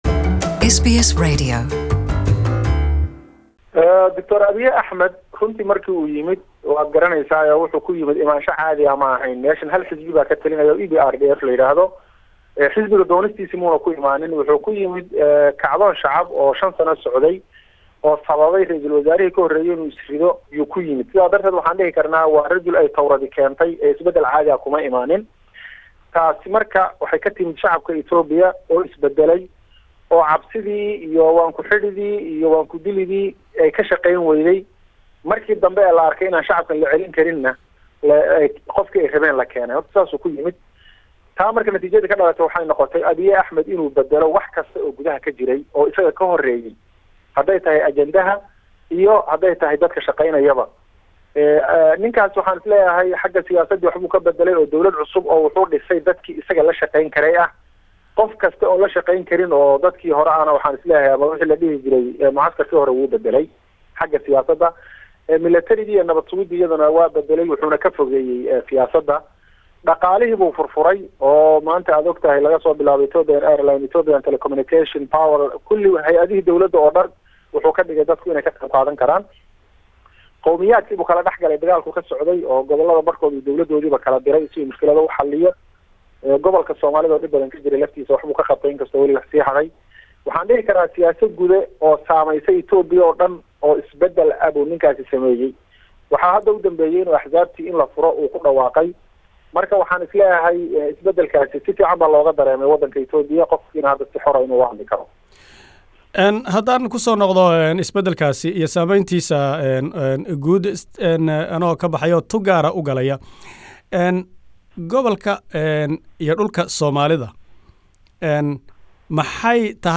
Iterview